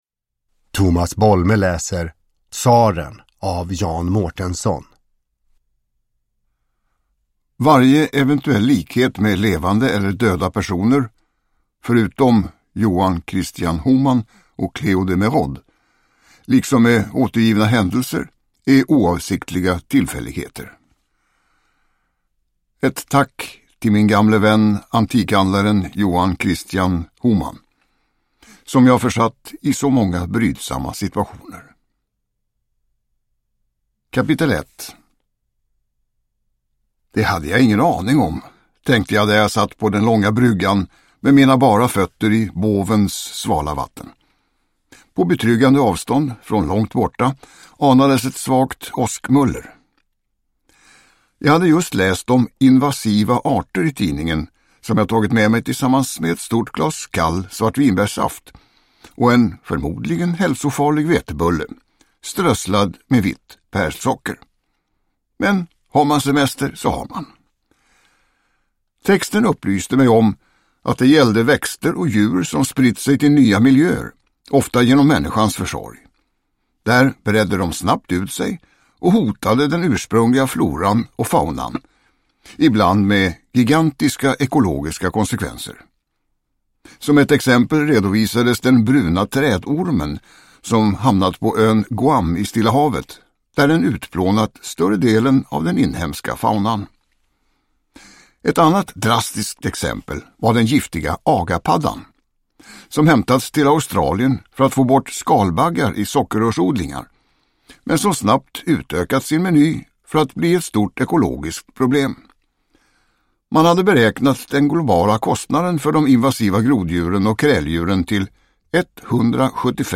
Tsaren – Ljudbok – Laddas ner
Uppläsare: Tomas Bolme